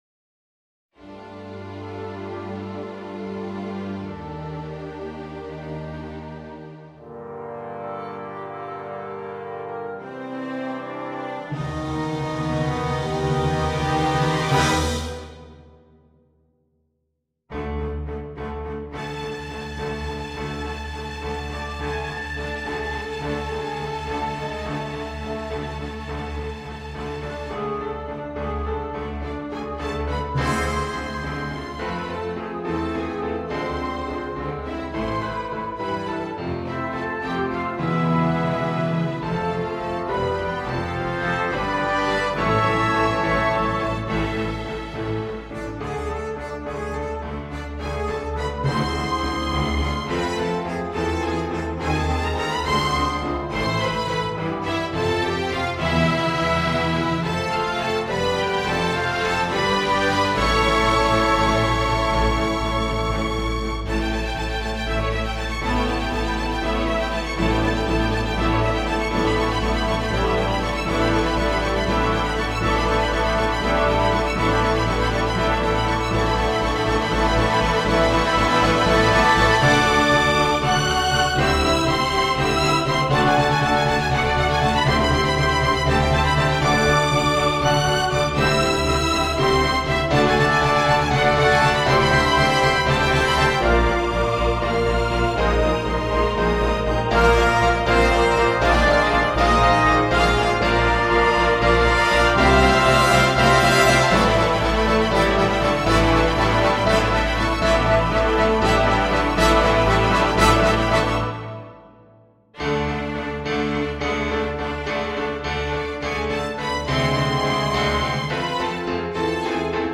Gattung: für Chor, Sinfonieorchester und Solisten